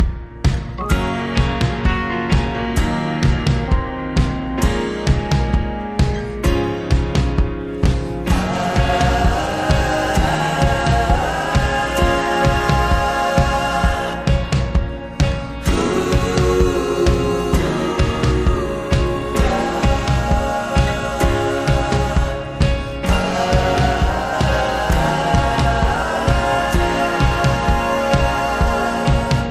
A healthy handful of chords, tautly played.